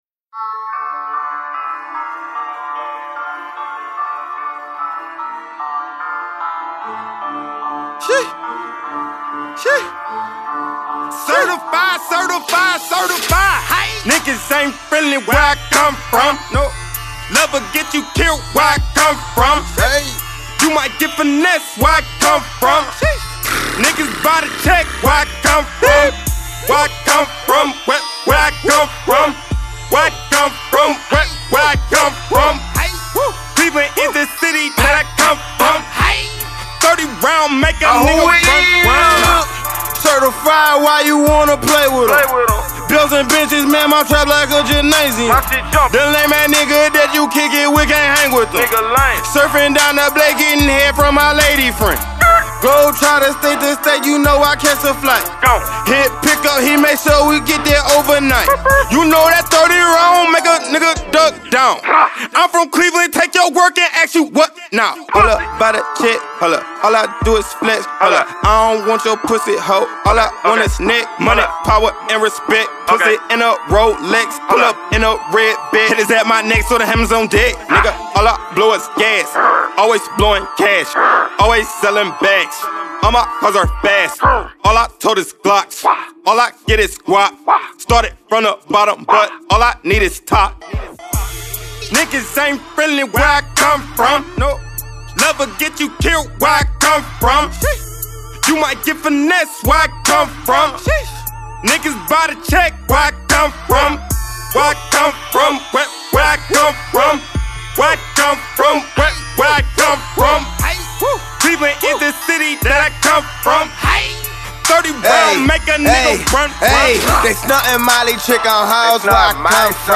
Indie